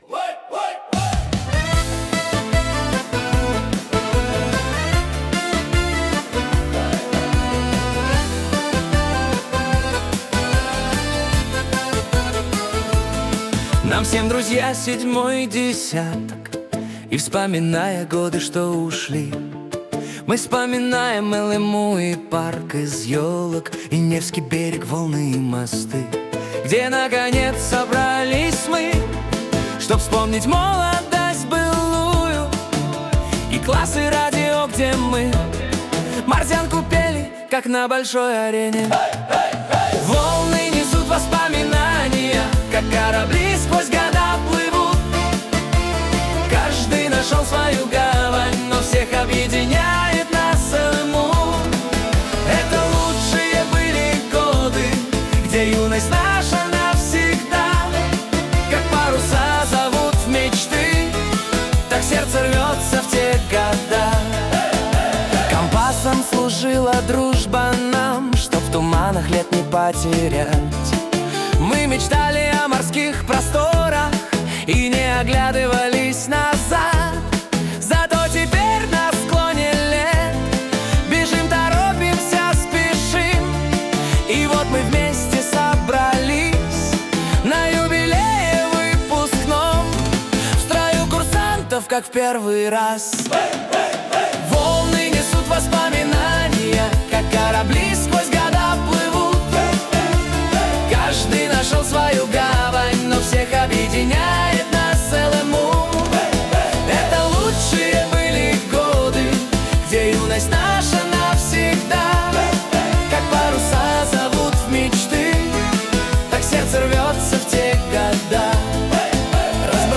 CROWDS & BRASS BANDS!
CROWDS & BRASS BANDS! 🎷 The Magic Builds at Tournai Carnival 2026! 🇧🇪✨